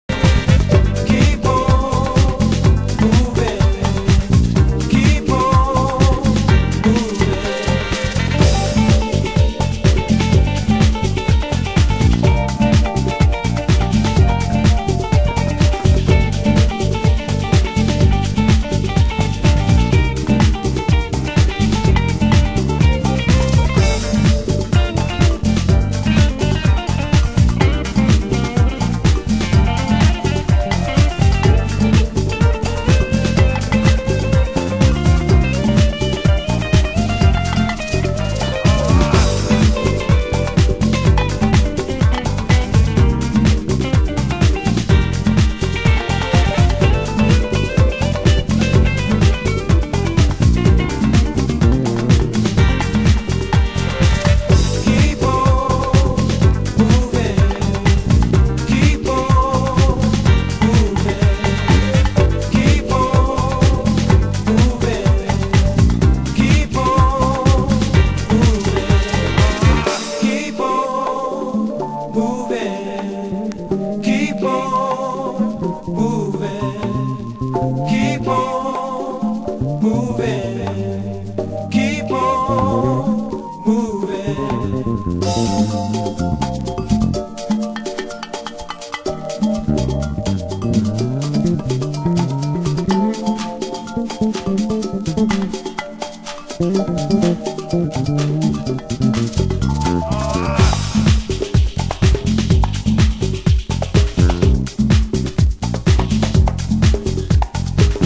II Deep House Mix